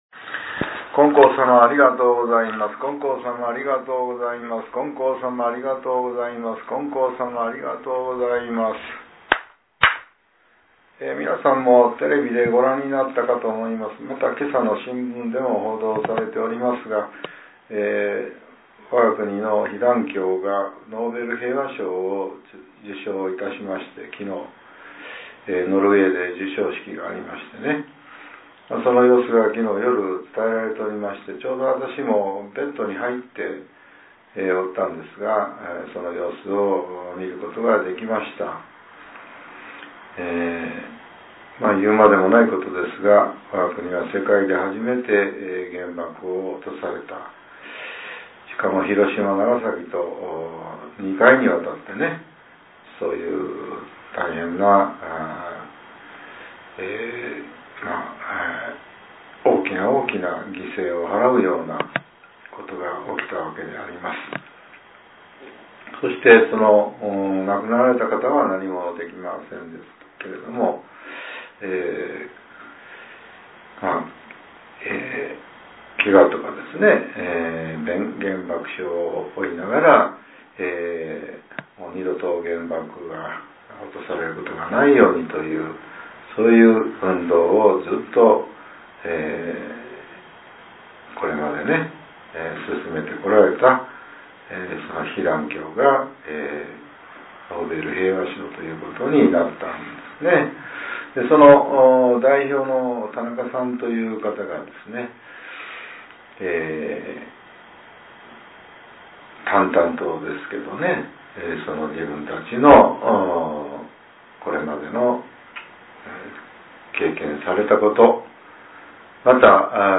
令和６年１２月１１日（朝）のお話が、音声ブログとして更新されています。